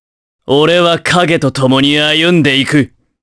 Roi-vox-select_jp.wav